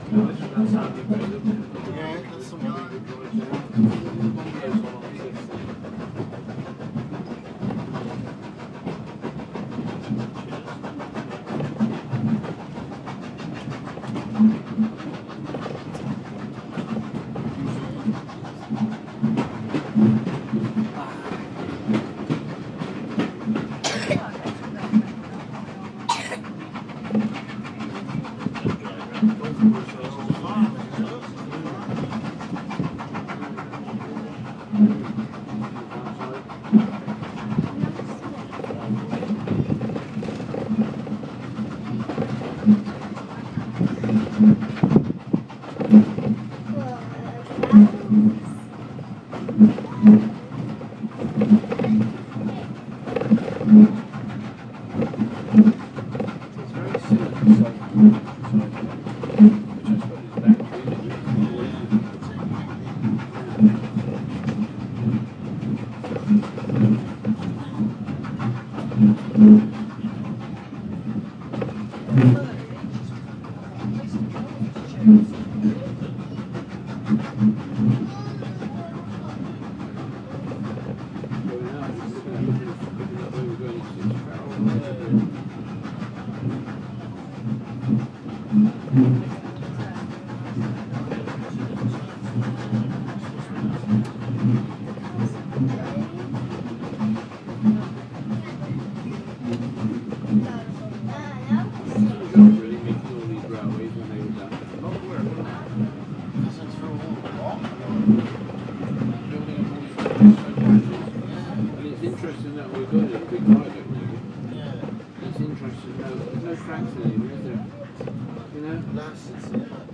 Soothing steam train sounds and arrival at Churston